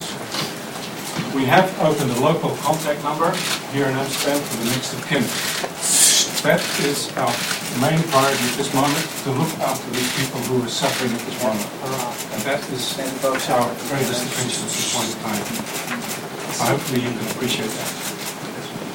One other remark that stood out at the chaotic press conference where journalists jostled for space and shouted to get a question in, concerned Malaysia Airlines choosing to fly over that part of Ukraine.